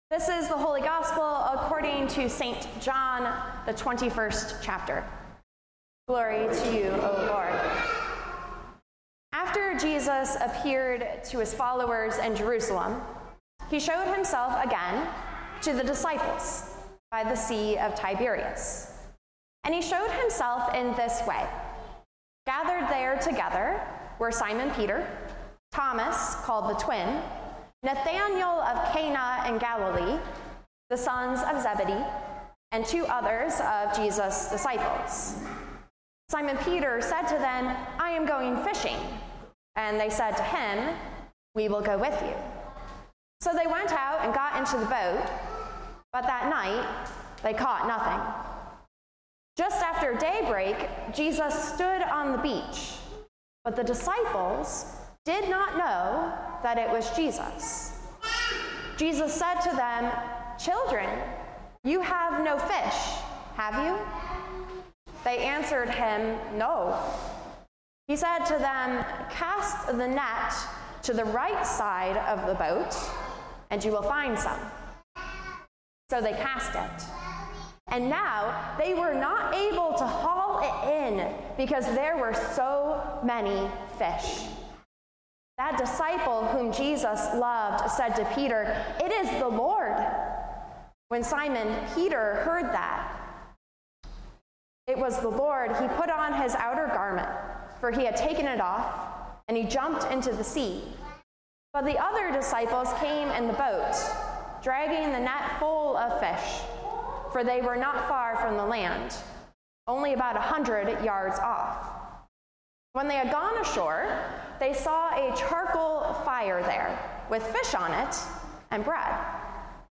Sermons | Grace Evangelical Lutheran Church